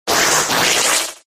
cry